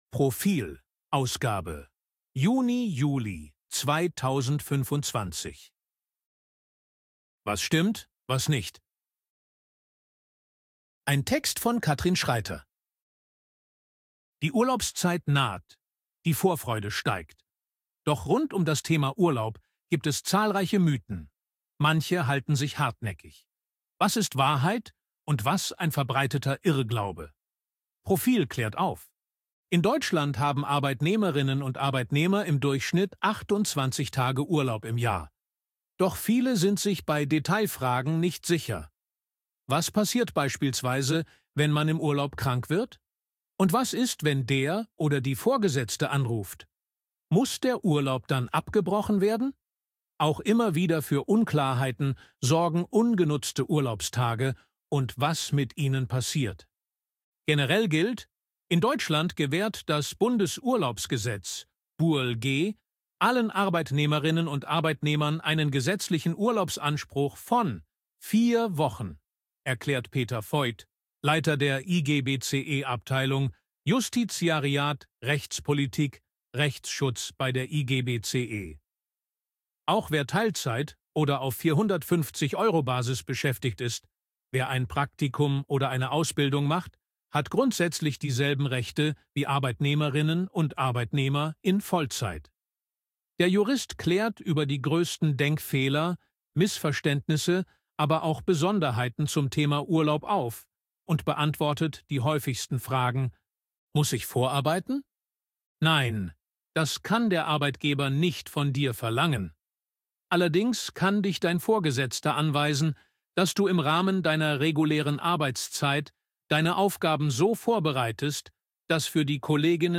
Artikel von KI vorlesen lassen
ElevenLabs_KI_Stimme_Mann_Service_Arbeit.ogg